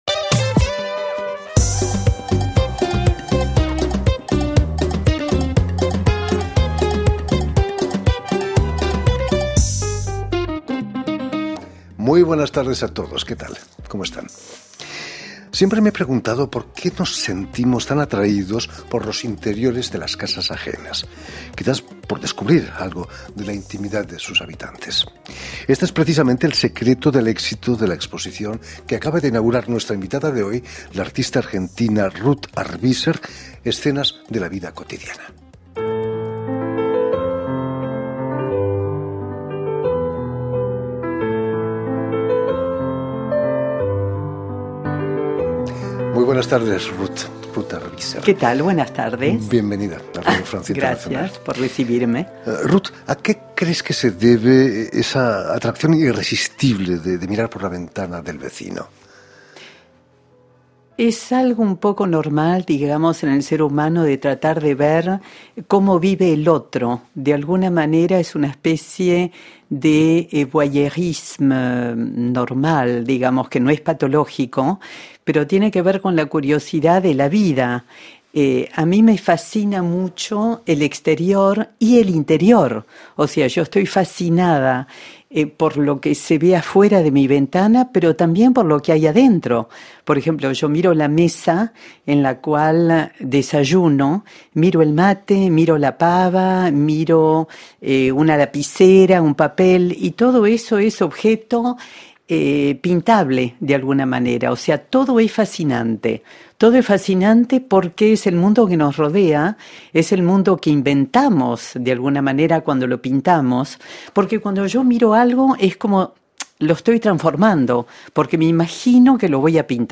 En "El invitado de RFI", Radio Francia Internacional recibe a un invitado en sus estudios, sobre la actualidad política o cultural.